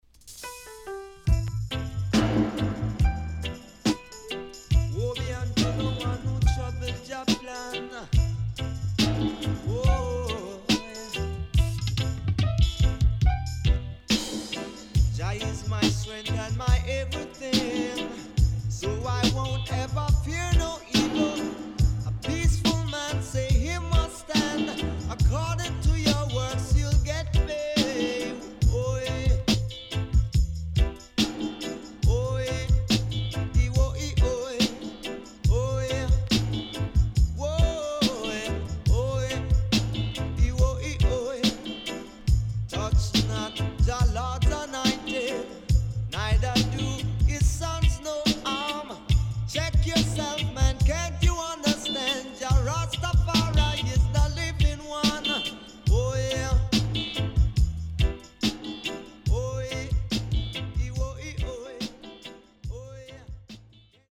HOME > LP [DANCEHALL]  >  SWEET REGGAE  >  定番70’s
SIDE A:少しチリノイズ入りますが良好です。